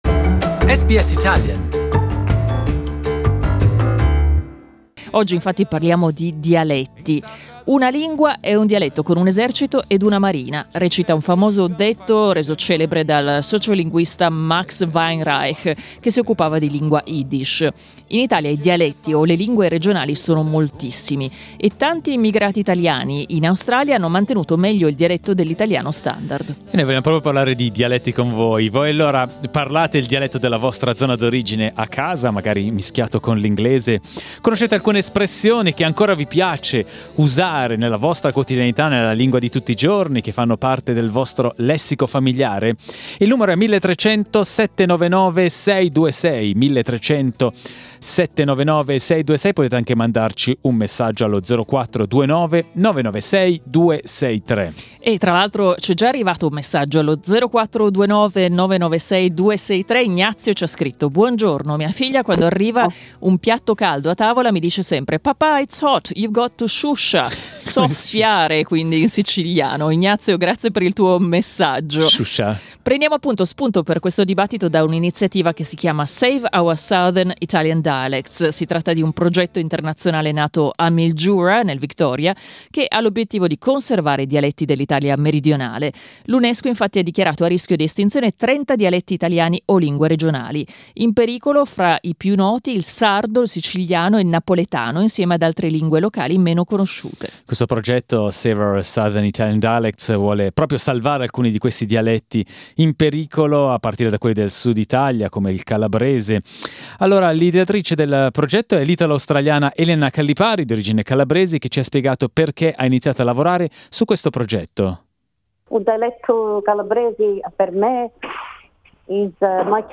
I dialetti sono ancora parlati da moltissimi italiani in Australia: abbiamo chiesto ad ascoltatori e ascoltatrici quale dialetto parlano e se hanno un'espressione dialettale a loro particolarmente cara.